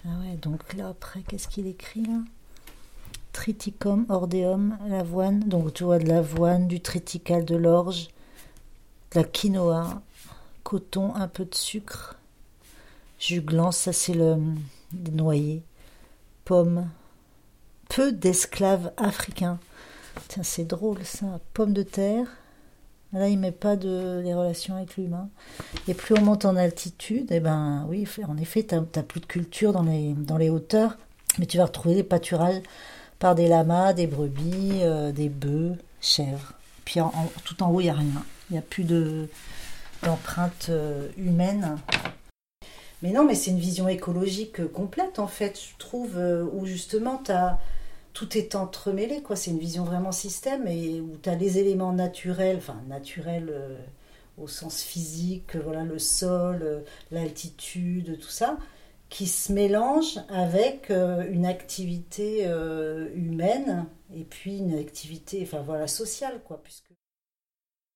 Ici elle commente le schéma Géographie des plantes équinoxiales ou Tableau Physique des Andes et Pays Voisins, d’après des observations et des mesures prises sur les lieux depuis le 10ème degré de la latitude boréale jusqu’au 10ème de la latitude australe entre 1799, 1800, 1801, 1802 et 1803 par Alexander De Humboldt et Aimé Bonpland .